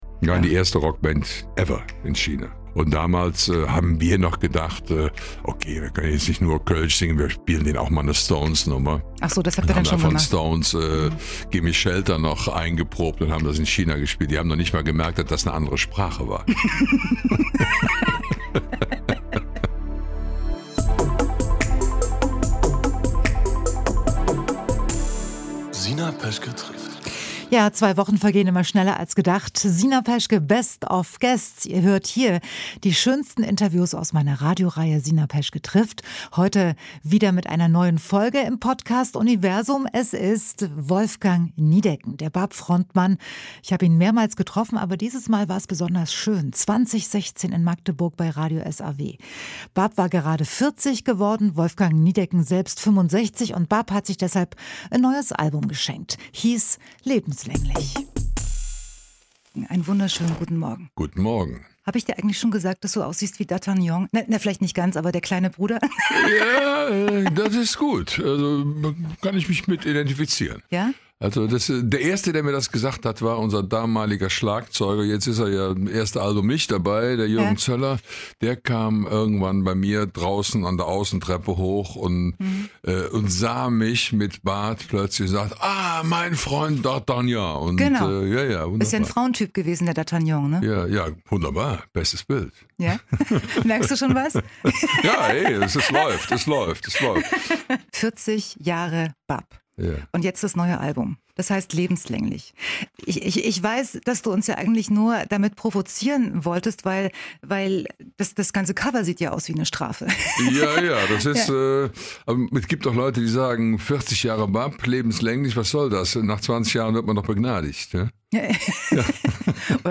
Wolfgang Niedecken, der BAP Frontmann. Ich habe ihn mehrmals getroffen, aber dieses Mal war´s besonders schön .Es war 2016 in Magdeburg .BAP war gerade 40 geworden und Wolfgang Niedecken selbst 65.